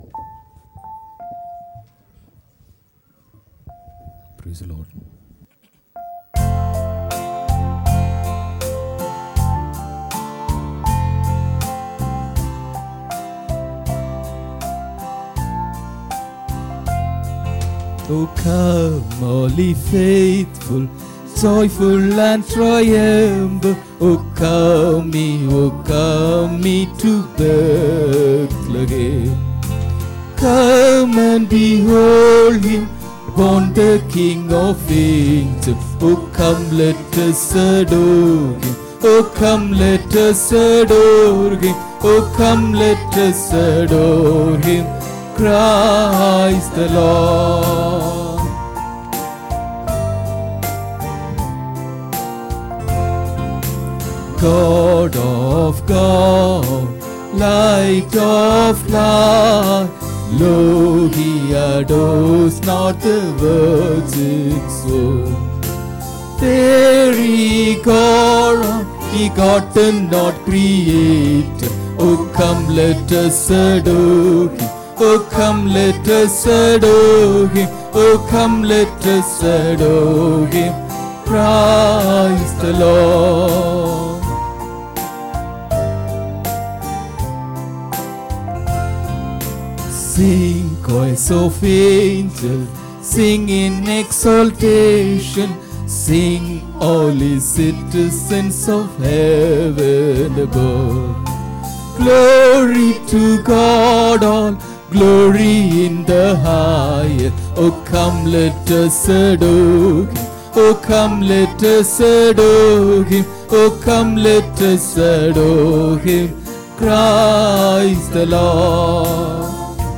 25 Dec 2023 Sunday Morning Service – Christ King Faith Mission